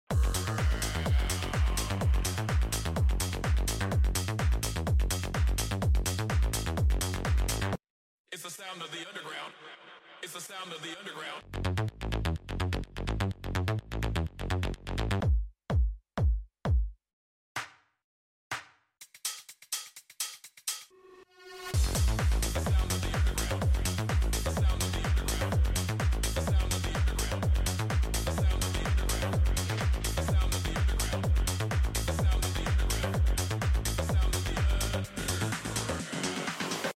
How to make Tech House